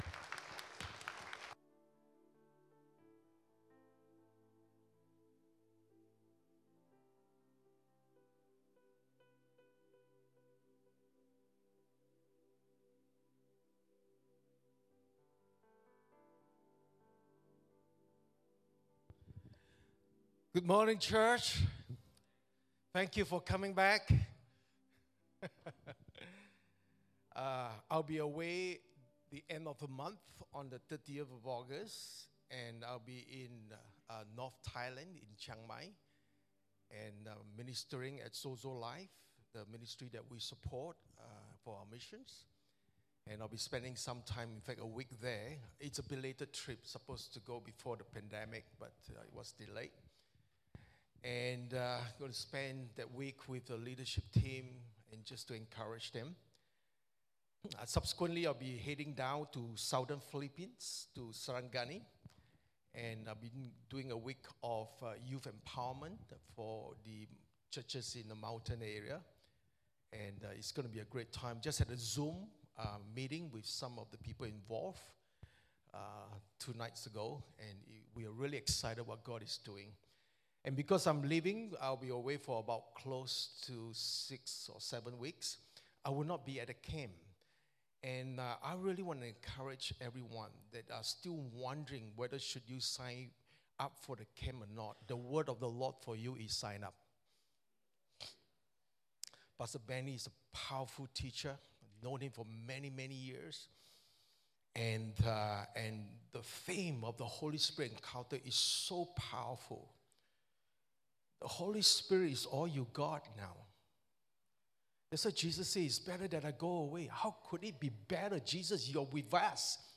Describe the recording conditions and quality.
English Worship Service 20th August 2023